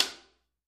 Knocking|Knocks | Sneak On The Lot
Door Knocks; Glass / Wood, Single